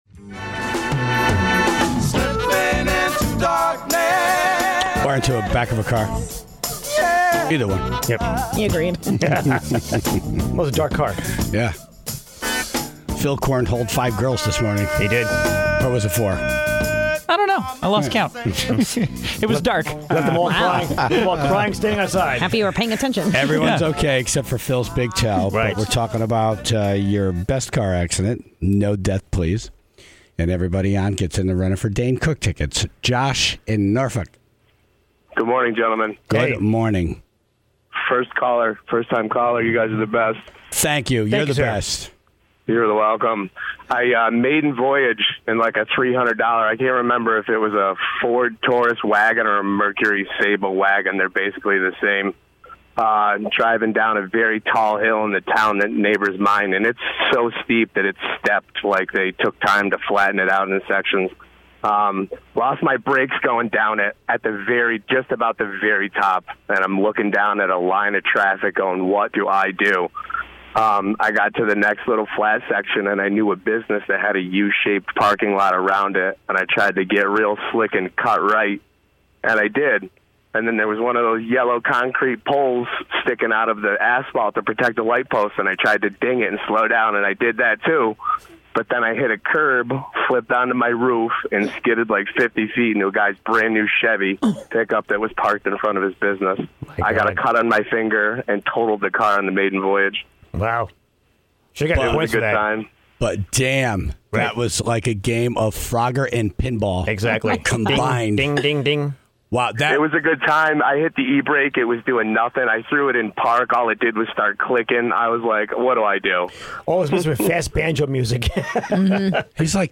then took calls from the Tribe about their car accidents.